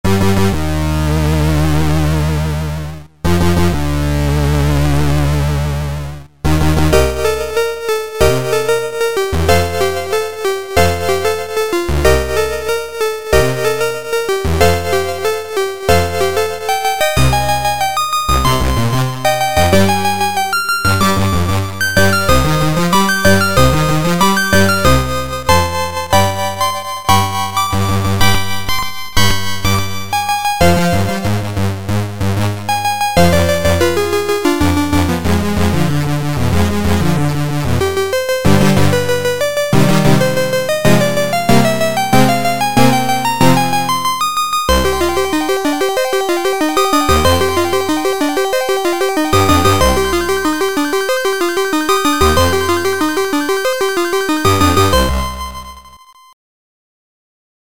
Sound Format: Soundmon 2